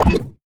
Robotic Back Button 2.wav